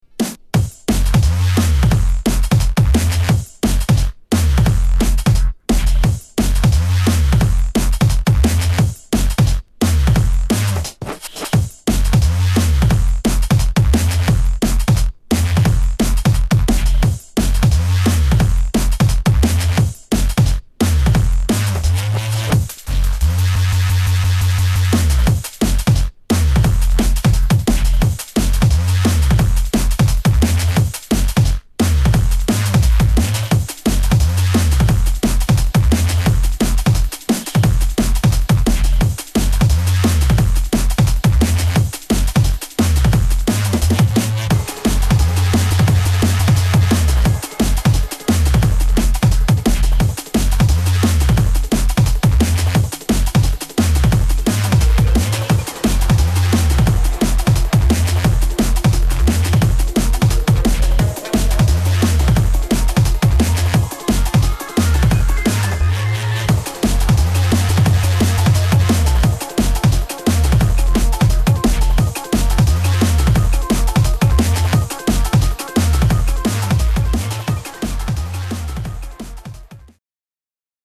DRUM'N'BASS / JUNGLE